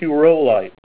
Help on Name Pronunciation: Name Pronunciation: Hureaulite + Pronunciation
Say HUREAULITE Help on Synonym: Synonym: Ca-hureaulite - Ca-bearing hureaulite   ICSD 34744   PDF 34-146